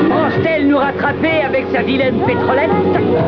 Sons ( doublages débiles :) )